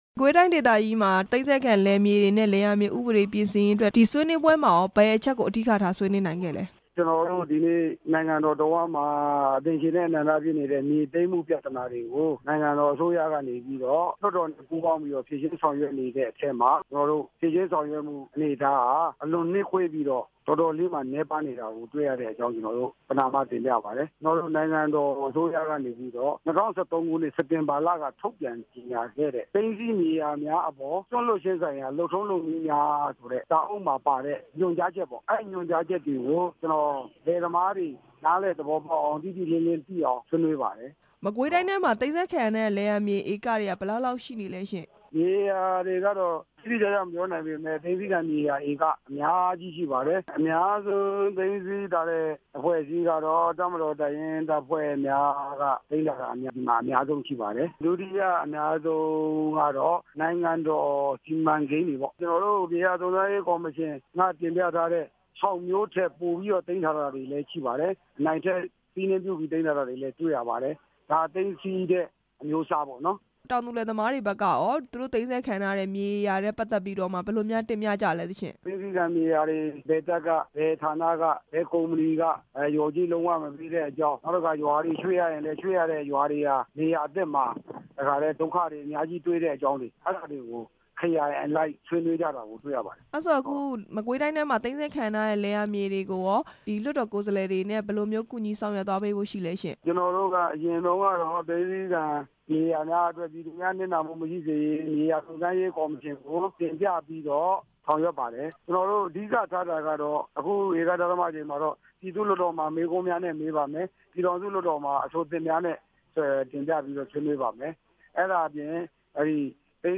လွှတ်တော်ကိုယ်စားလှယ် ဦးစိန်ထွန်းနဲ့ မေးမြန်းချက်